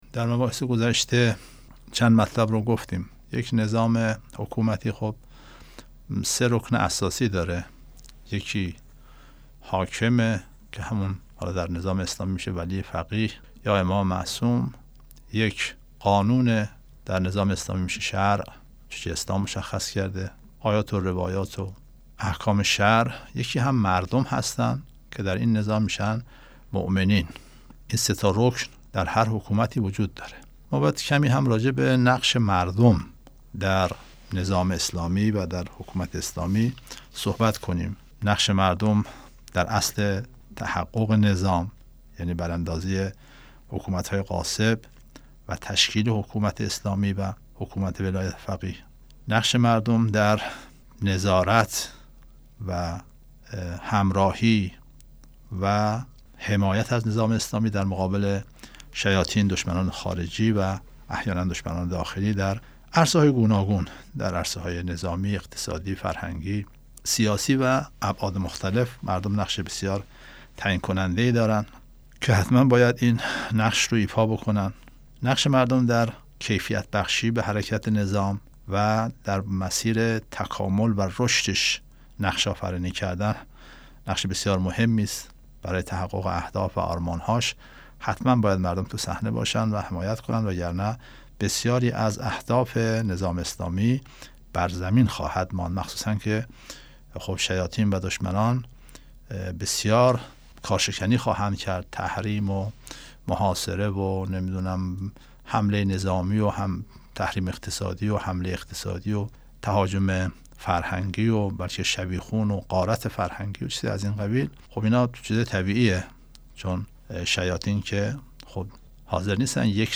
بخش سی و هفتم : نقش مردم در نظام اسلامی / مدت زمان سخنرانی : 7 دقیقه